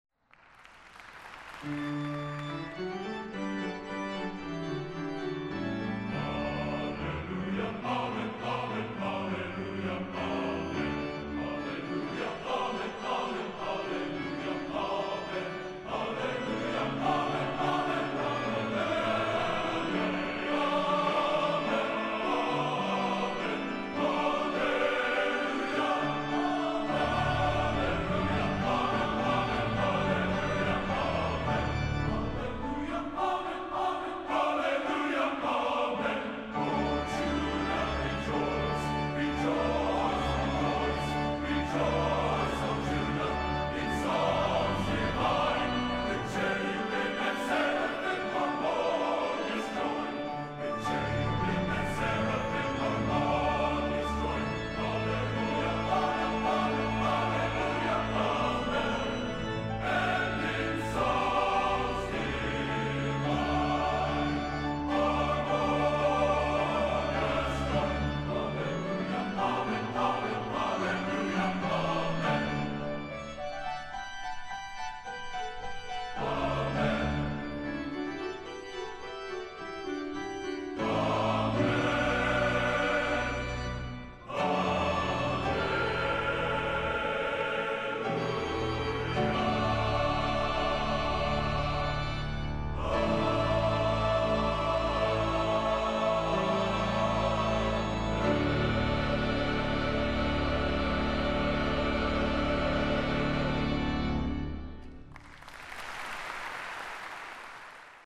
Location: West Lafayette, Indiana
Genre: Baroque | Type: